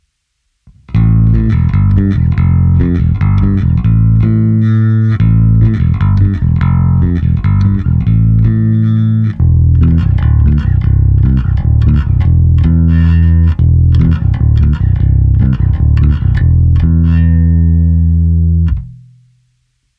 Nahrávky jsou bez simulace aparátu, nicméně jsou prohnané skrz kompresor a lampový preamp a použil jsem individuálně pro každou nahrávku i korekce přímo na baskytaře. Použité struny jsou nové niklové pětačtyřicítky Elixir.
Cívky 1 a 3 – zvuk ala '70 Jazz Bass